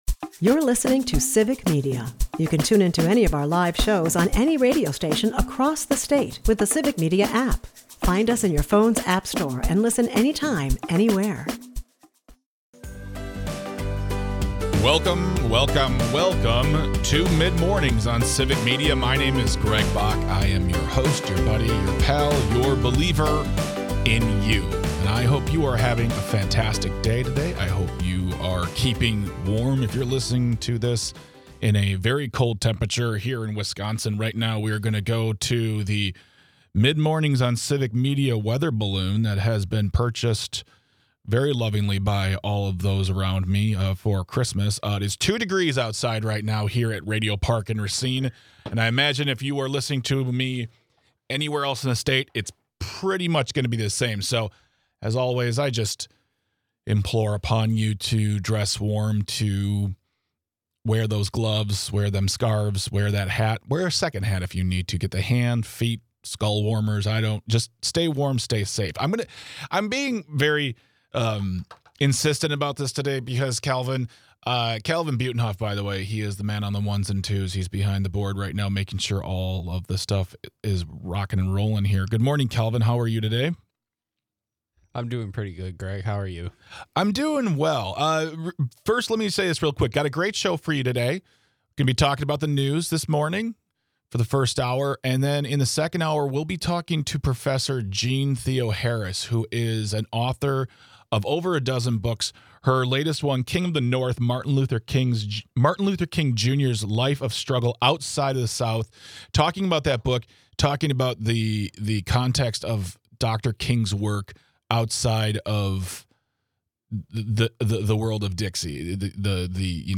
Welcome to Tuesday and even though its a cold one out there (Currently it's a balmy 9 degrees outside), we open the show up with a look at the news including the possible loss of major funding to Wisconsin Hospitals and why Democratic lawmakers are asking a GOP Congressman for help. We also discuss the farmers' bailout, which is set to hit bank accounts next month. And we hear from you about why this isn't the fix some say it is and why we can't learn from history.